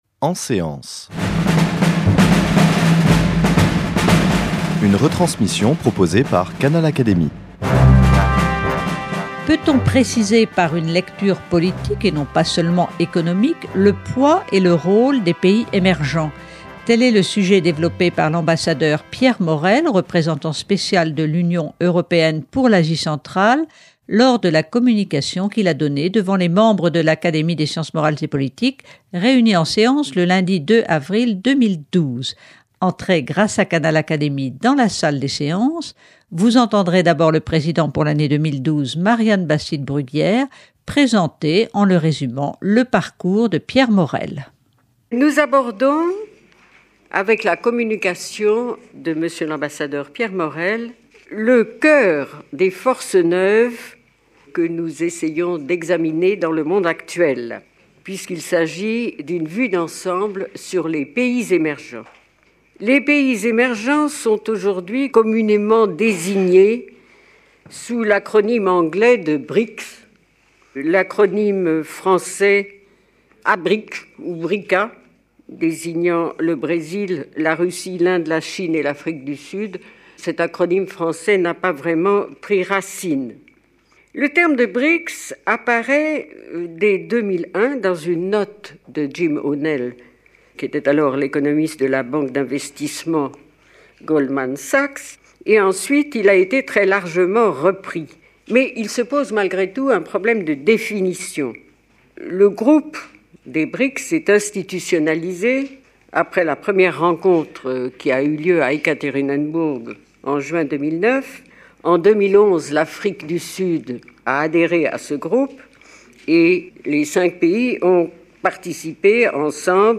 Aujourd'hui représentant spécial de l'Union européenne pour l'Asie centrale, Pierre Morel a donné une communication à l'Académie des sciences morales et politiques le lundi 2 avril 2012 sur le thème des pays émergents.